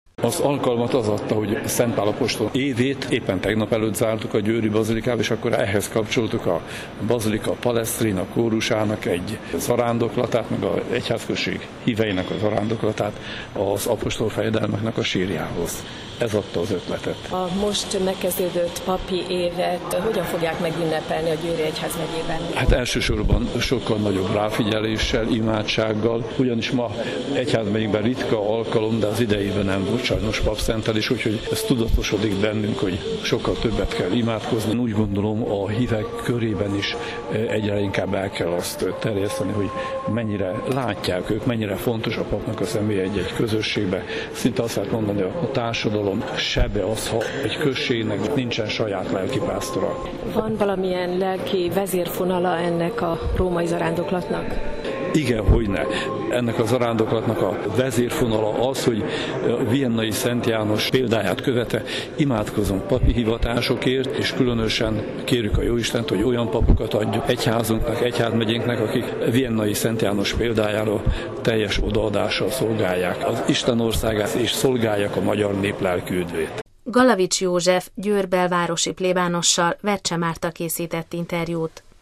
A szertartás után a bazilika sekrestyéjében készítettünk interjút